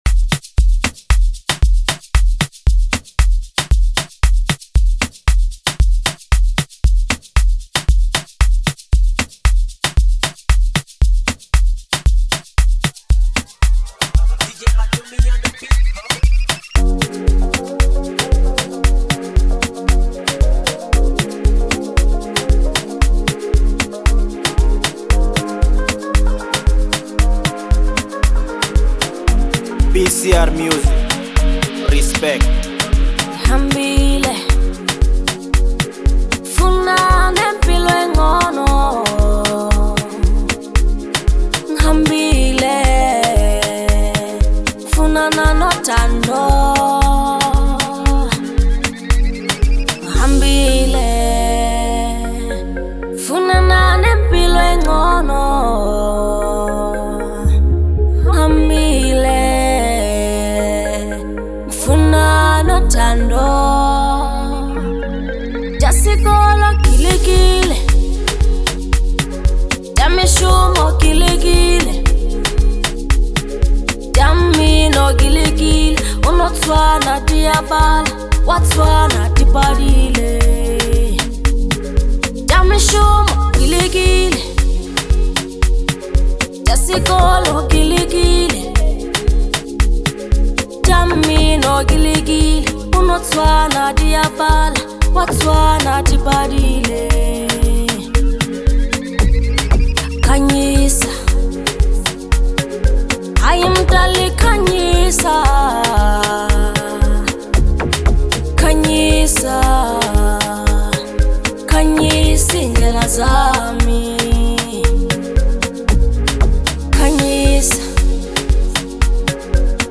Bolo HouseLekompo